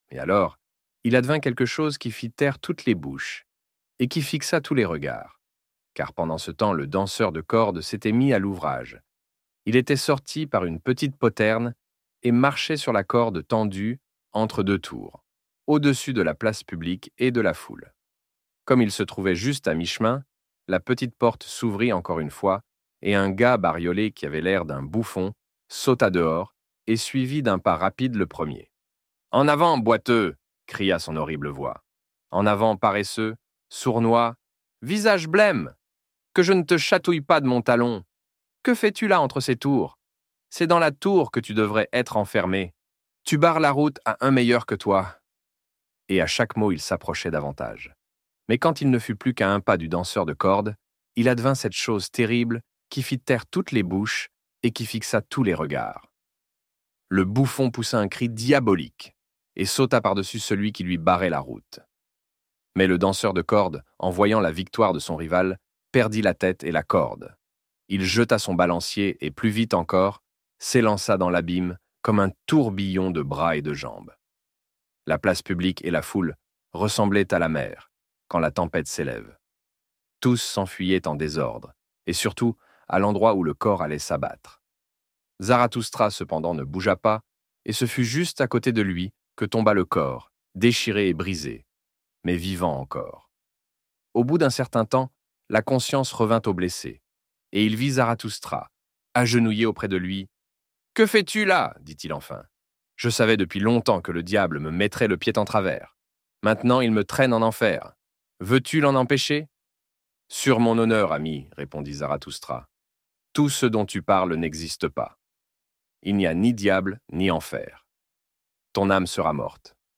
Ainsi Parlait Zarathoustra - Livre Audio